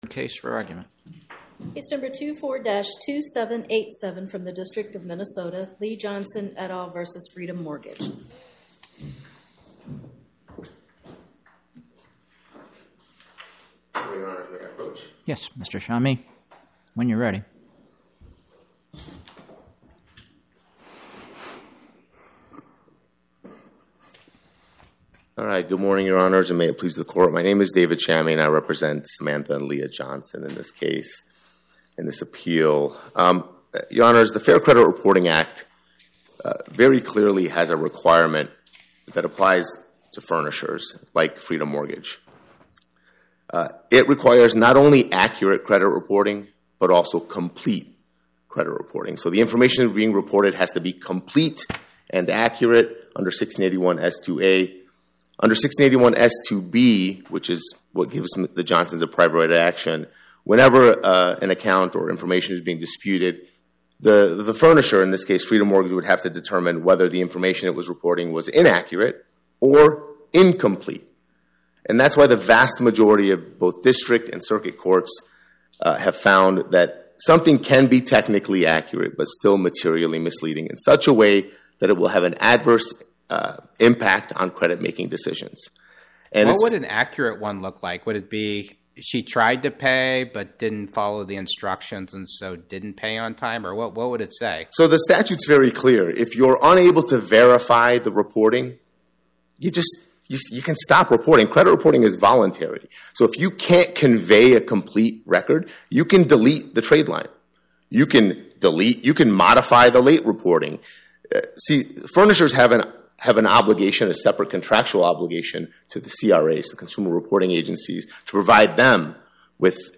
Podcast: Oral Arguments from the Eighth Circuit U.S. Court of Appeals Published On: Tue Oct 21 2025 Description: Oral argument argued before the Eighth Circuit U.S. Court of Appeals on or about 10/21/2025